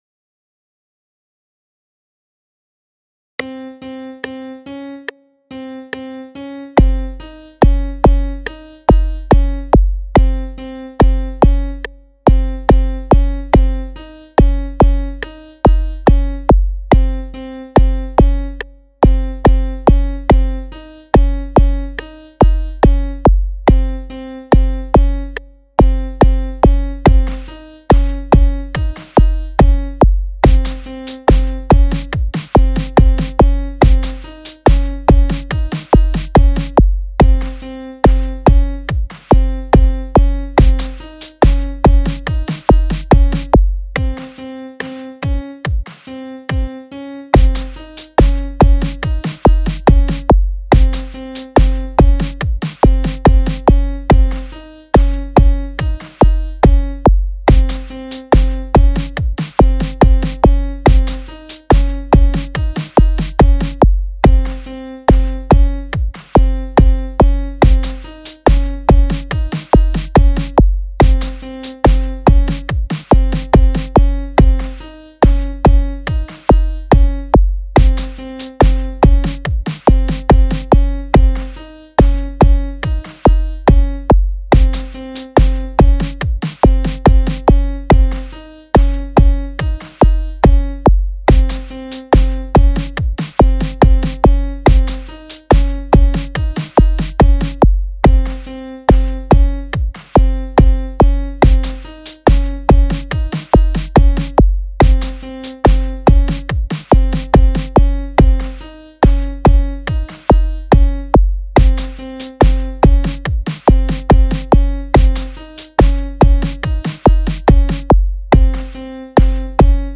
بیت با پیانو و بیس
Beat With Piano and Big bass